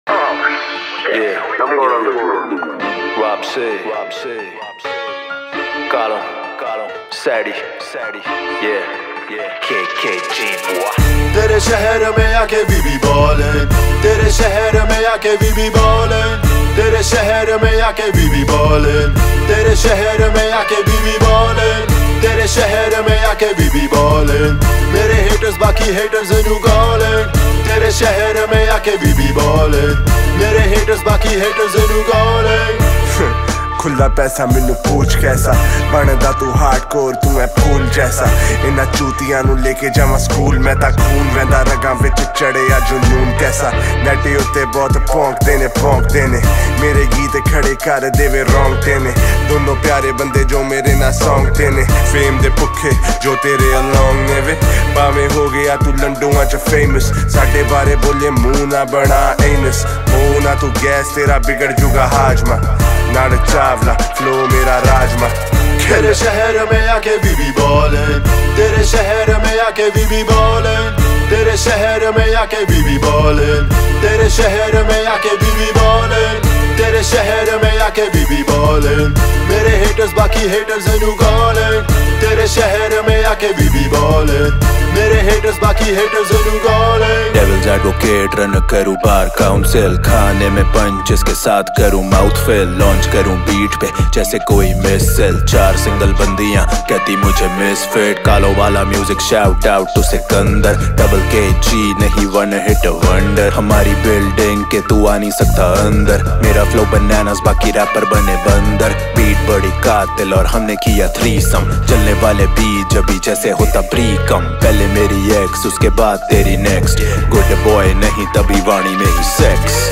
Single Indian Pop